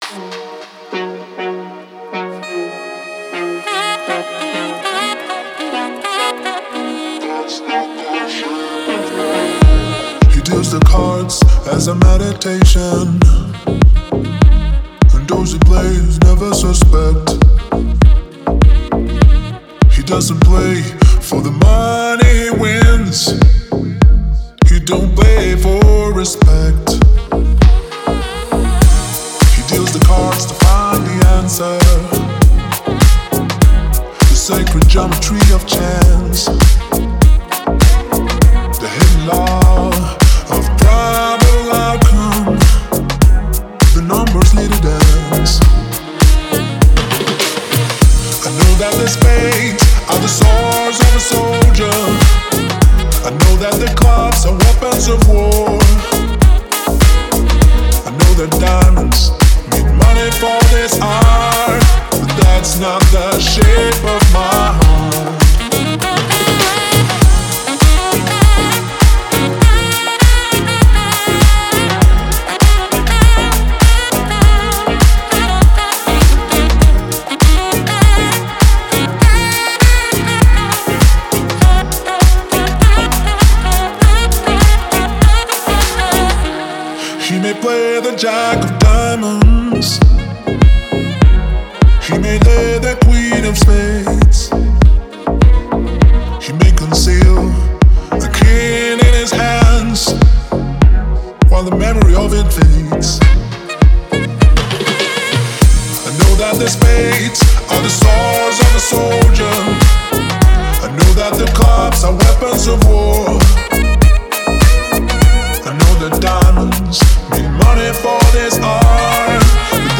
это захватывающая комбинация поп и электроники
добавляя современные ритмы и живую саксофонную партию.